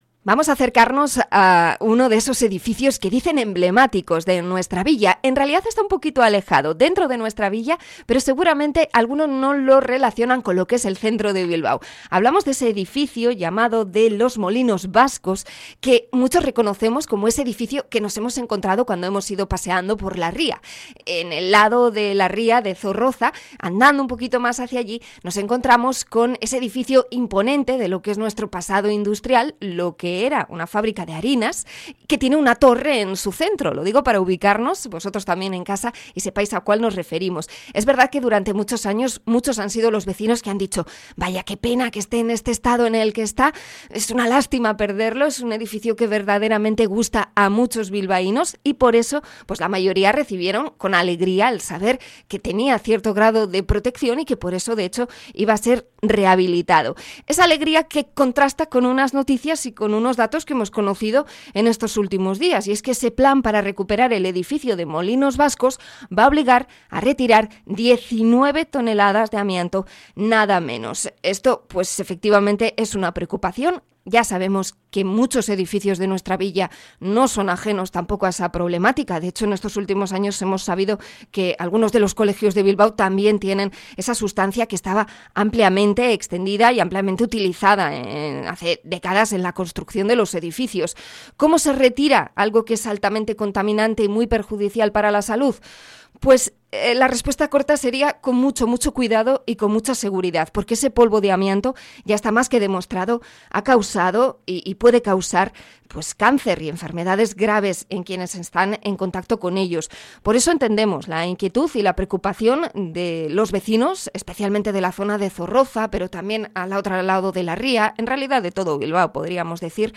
Entrevista a asociación vecinal por el amianto del edificio de Grandes Molinos Vascos